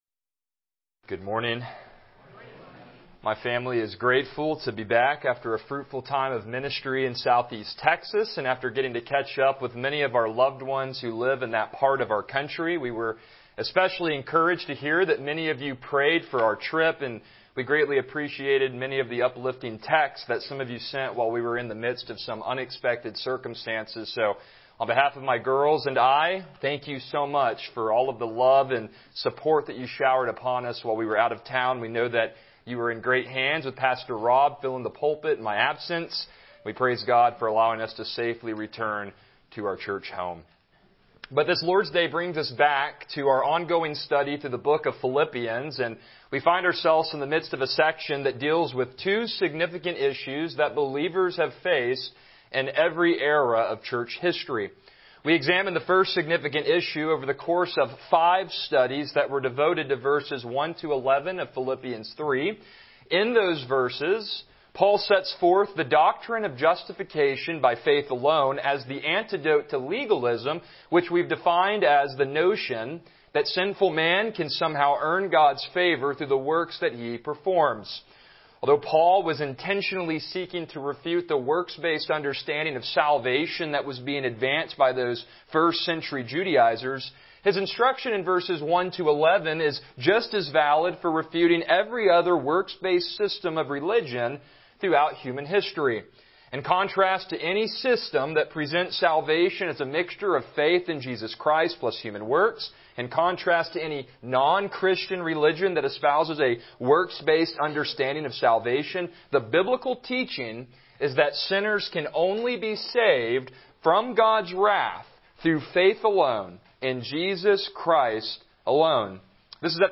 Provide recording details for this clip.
Passage: Philippians 3:18-19 Service Type: Morning Worship